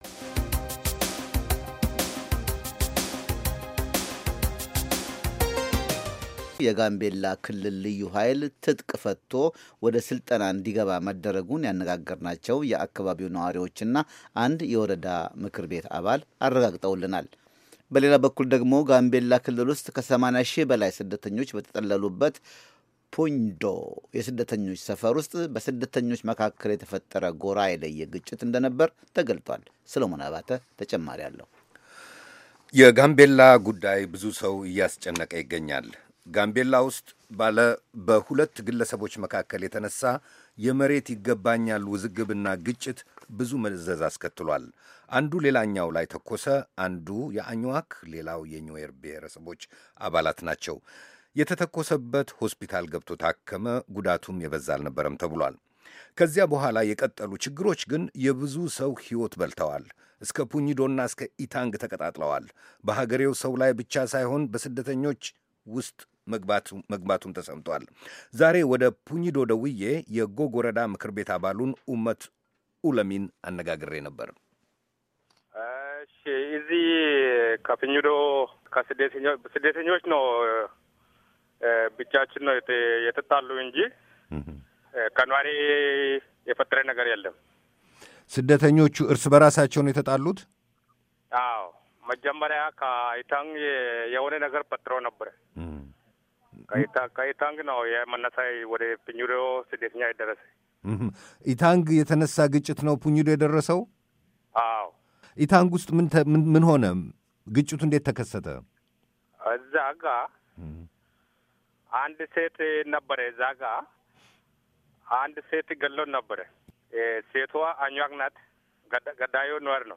የጋምቤላ ክልል ልዩ ኃይል ትጥቅ ፈትቶ ወደ ሥልጠና እንዲገባ መደረጉን ያነጋገርናቸው የአካባቢው ነዋሪዎችና አንድ የወረዳ ምክር ቤት አባል አረጋግጠውልናል፡፡